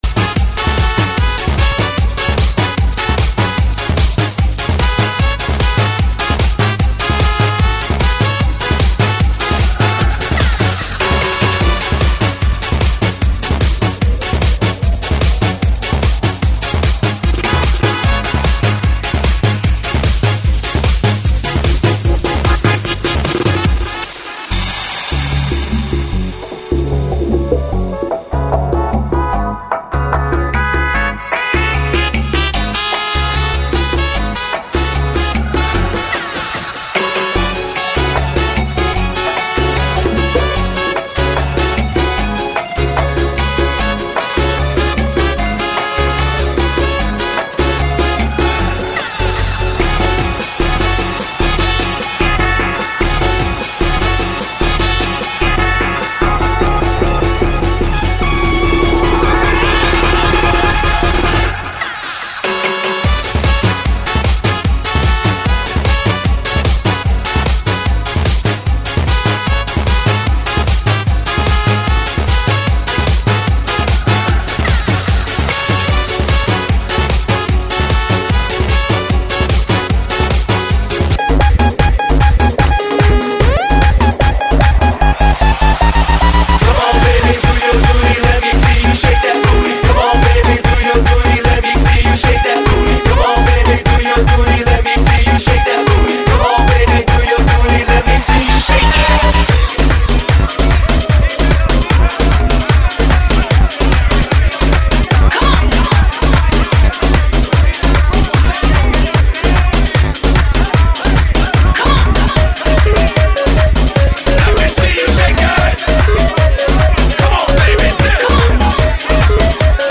GENERO: DANCE – RETRO – REMIX